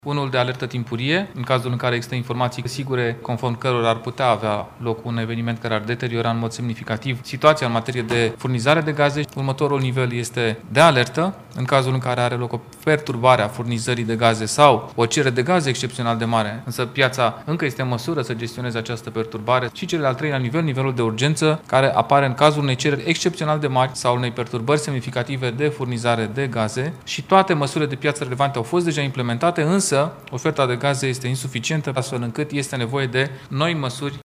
Purtătorul de cuvânt al Guvernului, Dan Cărbunaru a spus că situațiile de criză vor fi gestionate de Ministerul Energiei și vor exista 3 niveluri de criză, în funcție de gravitate lor.